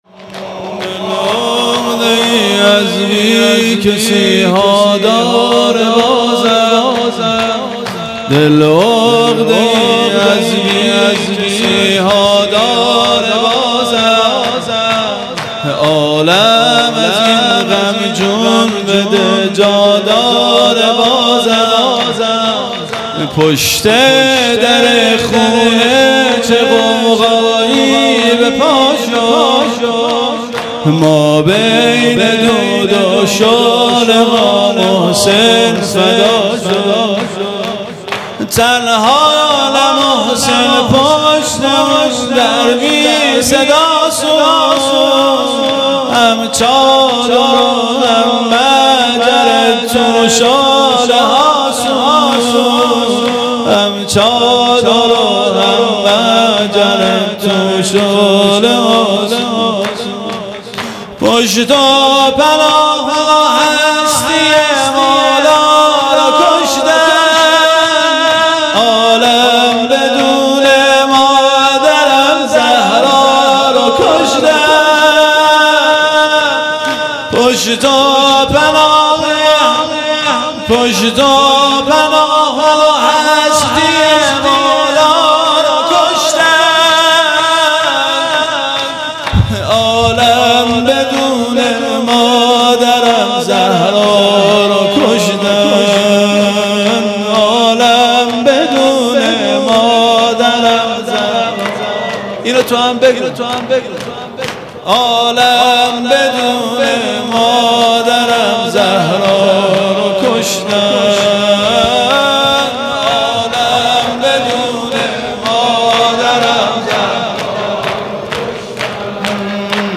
هیئت مکتب الزهرا(س)دارالعباده یزد